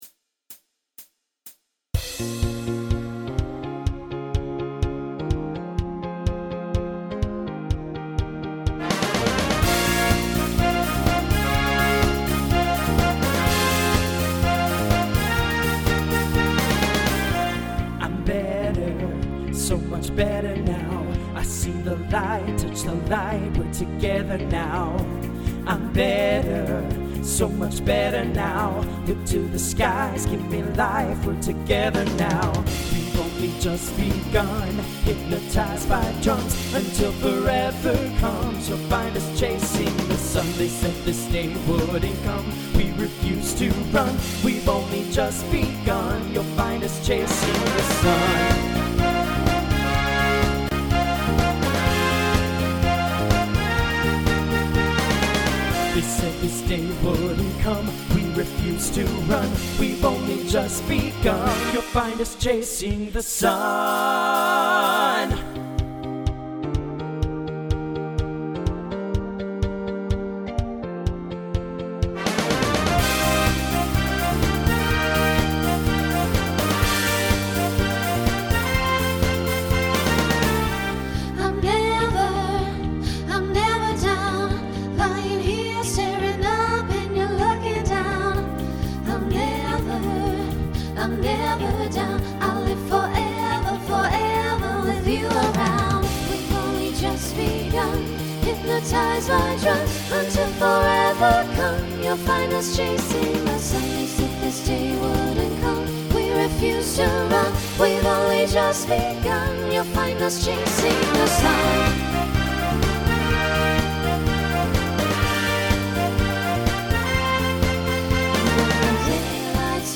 TTB/SSA
Instrumental combo
Pop/Dance , Rock